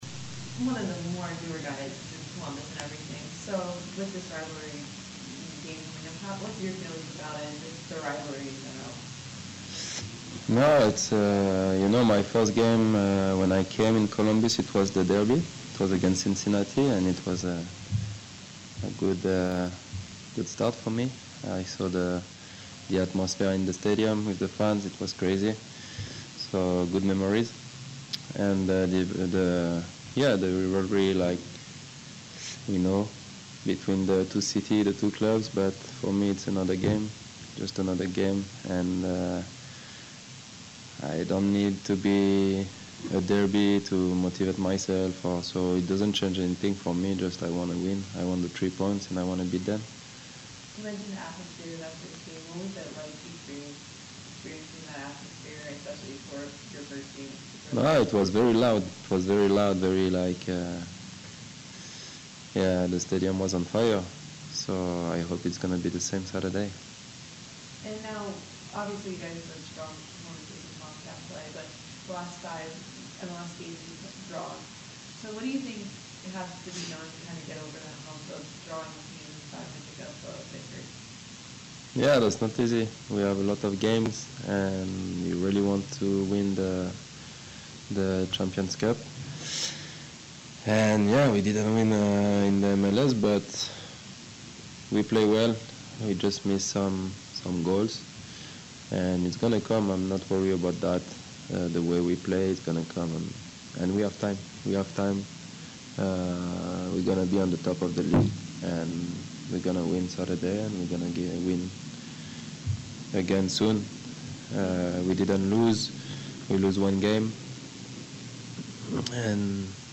‘Hell Is Real’ Derby 1 of 2024 is coming Saturday, May 11, 2024, FC Cincinnati at The Crew; Defender Rudy Camacho previews match
Rudy-Camacho-Crew-defender-previews-FC-Cincinnati-Week-Hell-Is-Real-Derby-May-2024.mp3